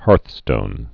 (härthstōn)